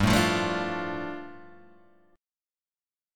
G 7th Suspended 2nd